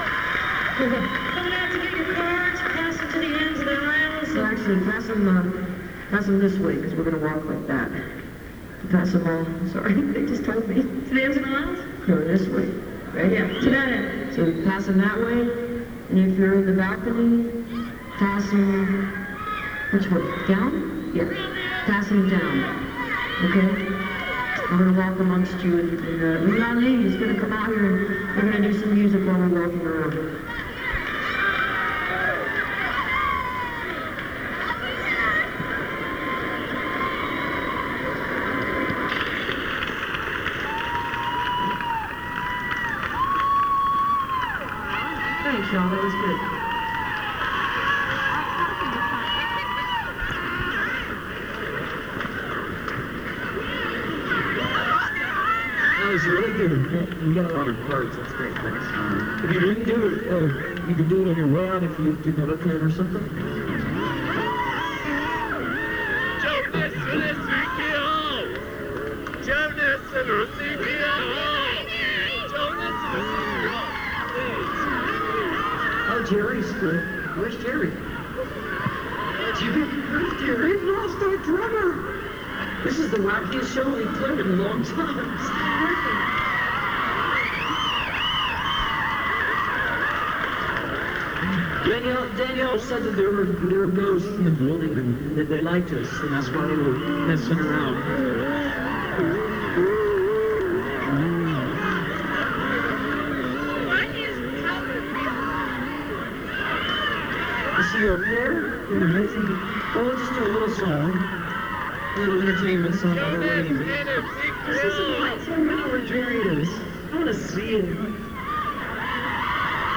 lifeblood: bootlegs: 1997-09-21: township auditorium - columbia, south carolina